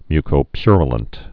(mykō-pyrə-lənt, -yə-lənt)